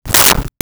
Whip 03
Whip 03.wav